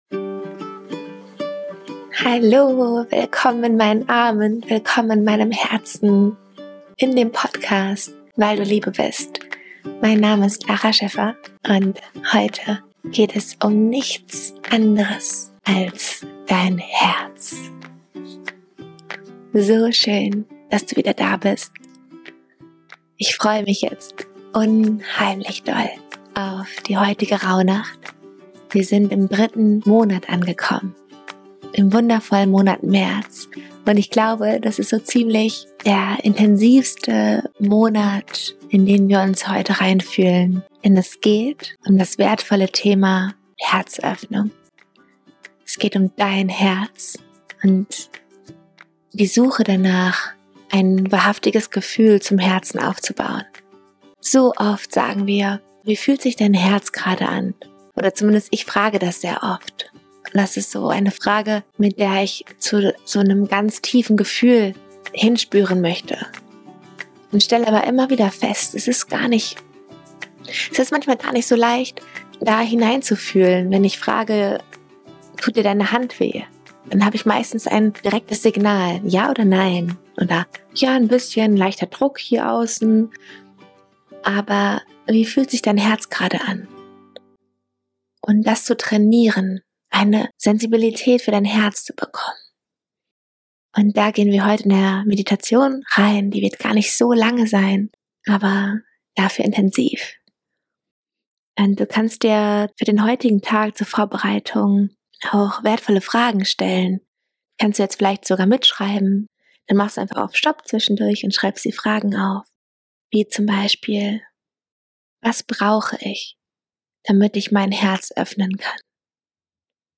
Diese kurze, liebevolle Meditation lädt dich ein, eine ganz neue Verbindung zu den verborgenen und tiefsten Wünschen deines Herzens aufzubauen und Fragen an deinen Herzenswarm zu richten.